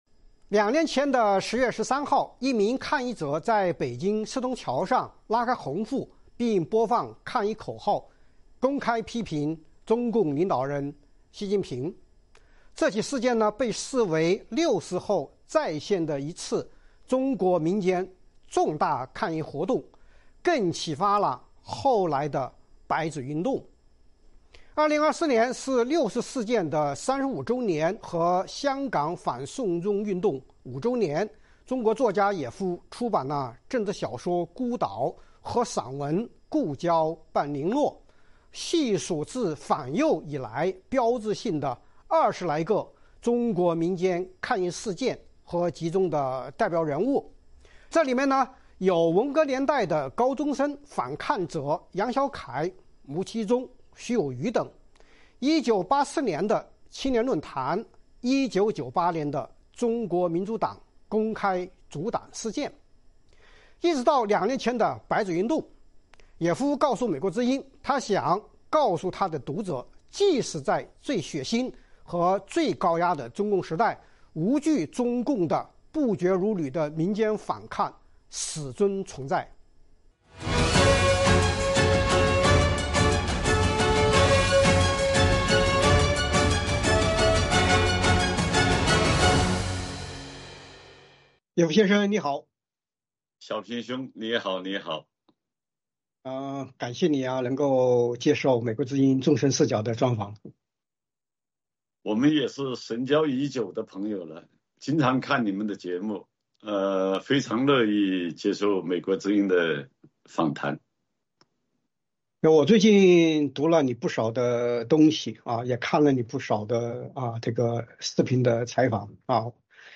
专访野夫：中共建政后的民间反抗事件和人物